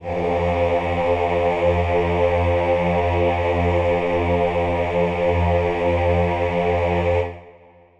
Choir Piano (Wav)
F2.wav